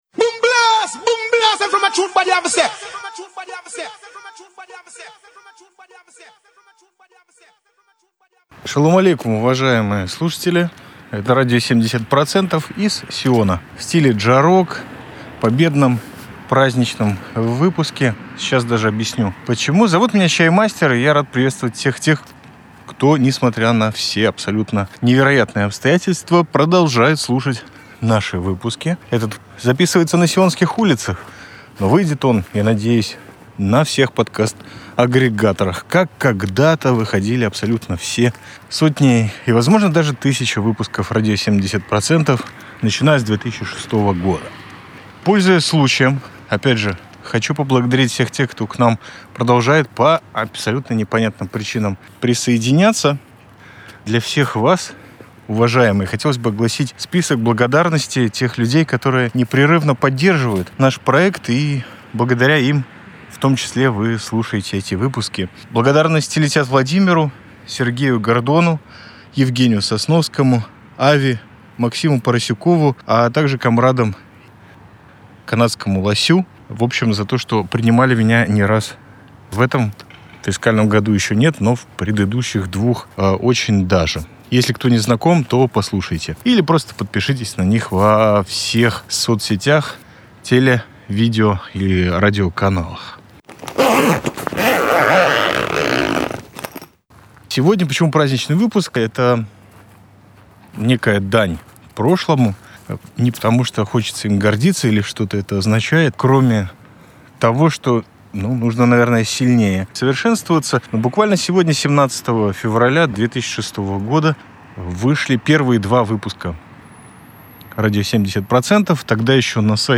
17-ое февраля — дань прошлому. Стриткаст с улиц Сиона, посвящённый 19 года обитания Радио 70% в интервебе.